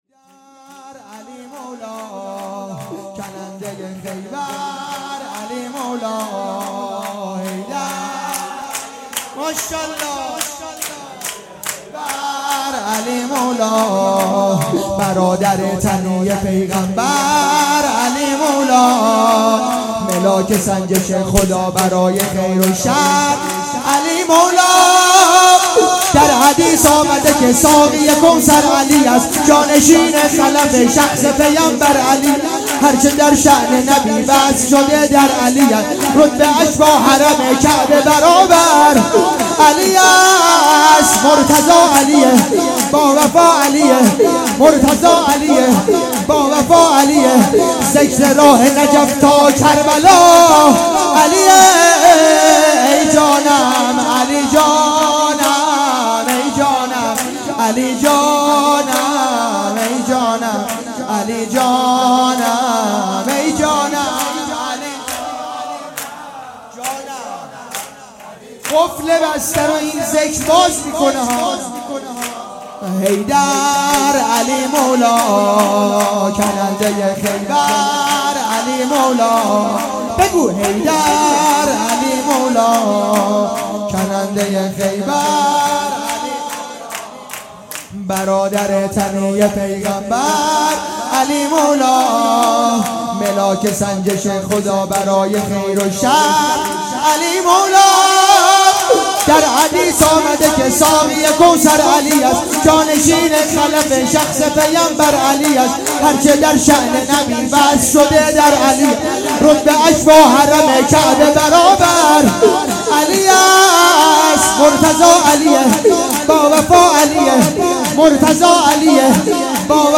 حیدر علی مولا _ شور
جشن ولادت امام باقر علیه السلام